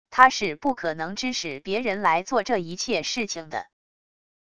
他是不可能支使别人来做这一切事情的wav音频生成系统WAV Audio Player